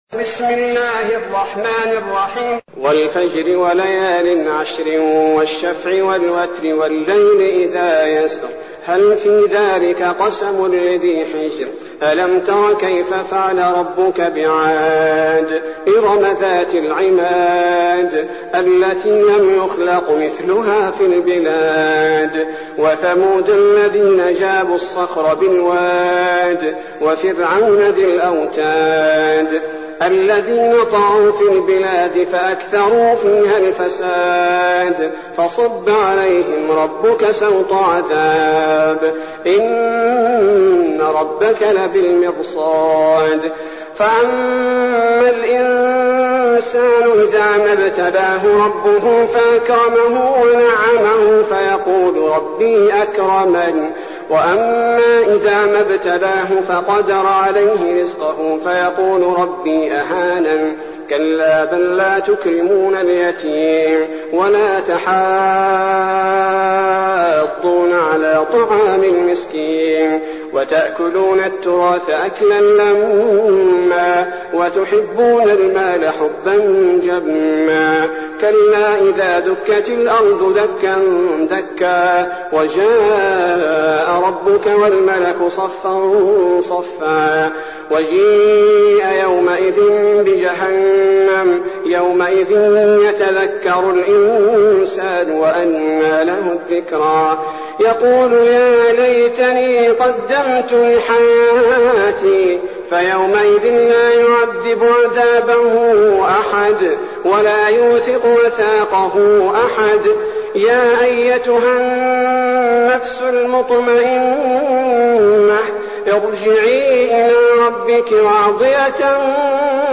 المكان: المسجد النبوي الشيخ: فضيلة الشيخ عبدالباري الثبيتي فضيلة الشيخ عبدالباري الثبيتي الفجر